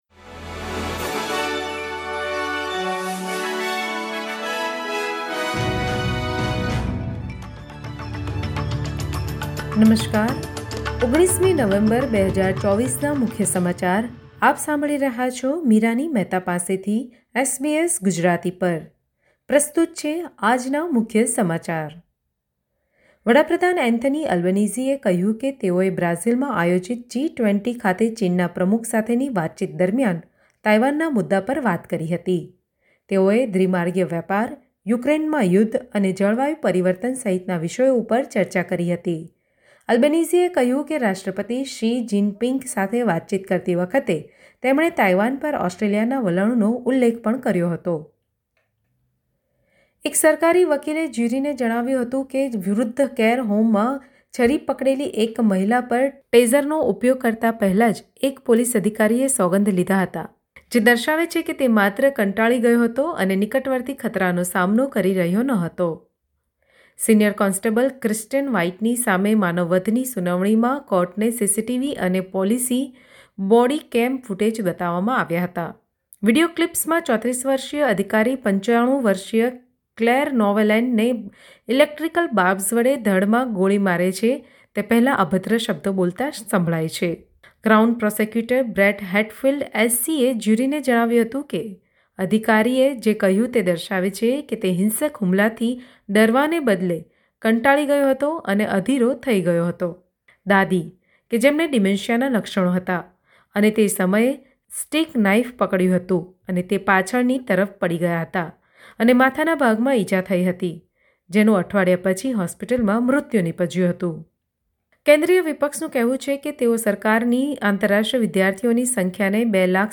SBS Gujarati News Bulletin 19 November 2024